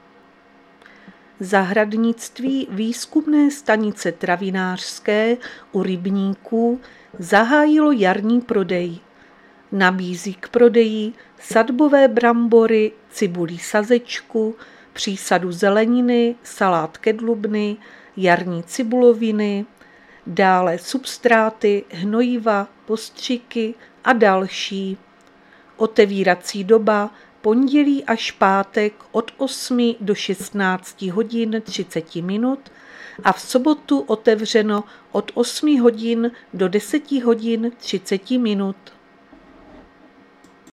Záznam hlášení místního rozhlasu 10.3.2026